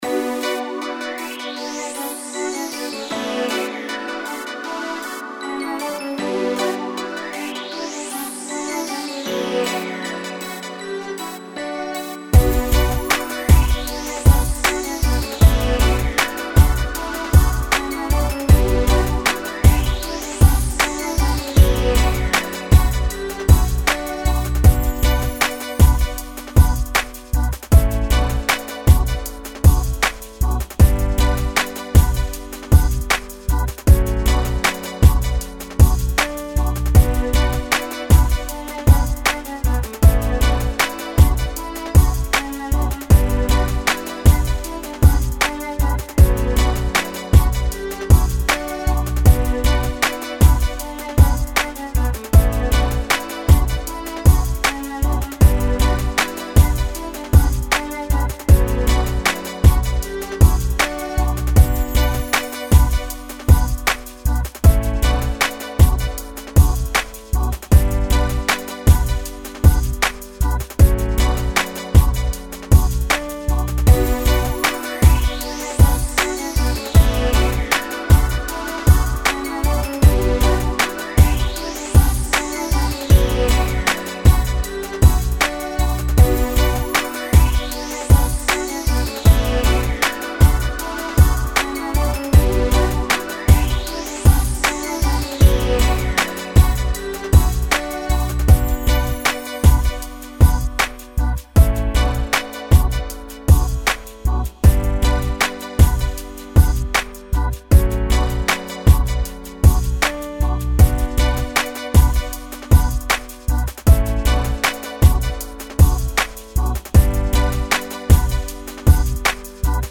78 BPM. Sad, chill type southern beat.
Strings, synths, and organs.